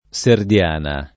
Serdiana [ S erd L# na ]